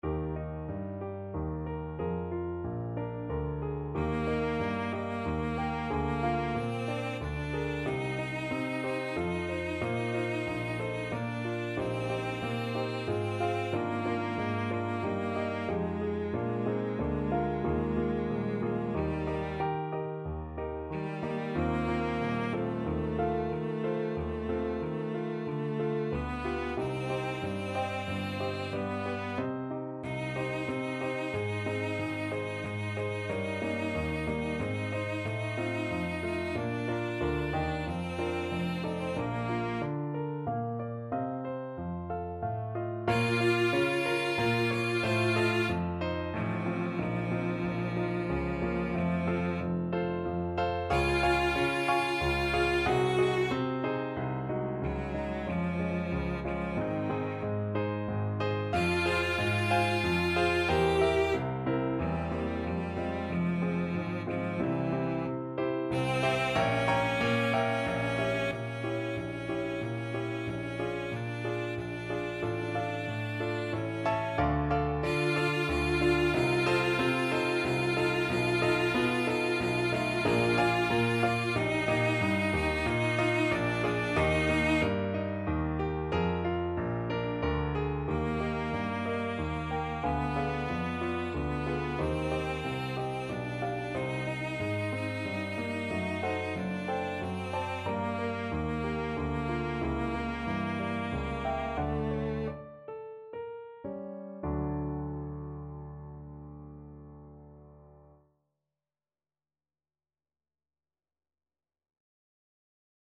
Hostias Cello version
Cello
3/4 (View more 3/4 Music)
~ = 92 Larghetto
Eb major (Sounding Pitch) (View more Eb major Music for Cello )
Classical (View more Classical Cello Music)